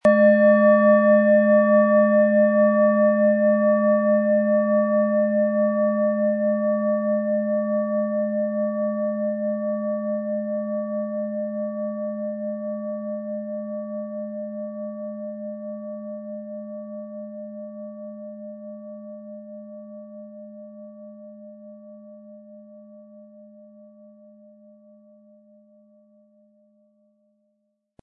Tibetische Universal-Klangschale, Ø 18,8 cm, 600-700 Gramm, mit Klöppel
Um den Original-Klang genau dieser Schale zu hören, lassen Sie bitte den hinterlegten Sound abspielen.
Der passende Schlegel ist umsonst dabei, er lässt die Schale voll und harmonisch tönen.
MaterialBronze